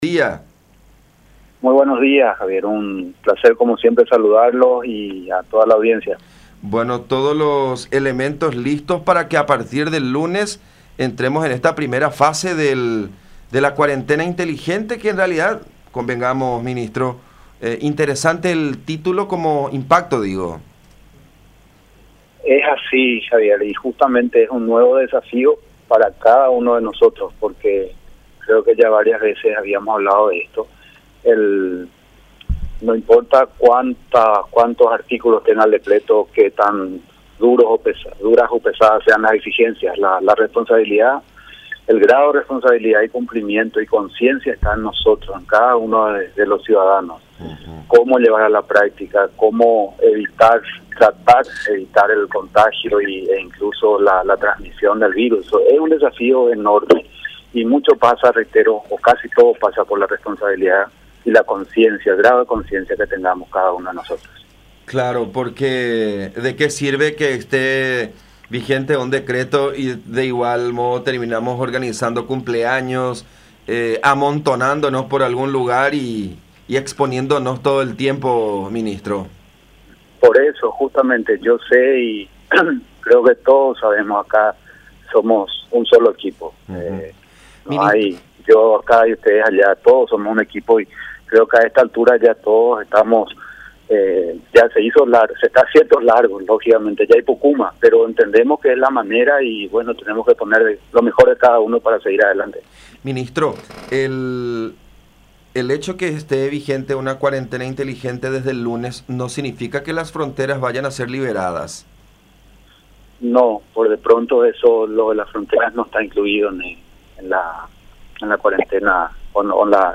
“Cerca de 700 de ellos han completado el periodo de cuarentena, fueron sometidos a los tests correspondientes y fueron transportados hasta sus respectivos domicilios”, dijo González en diálogo con La Unión.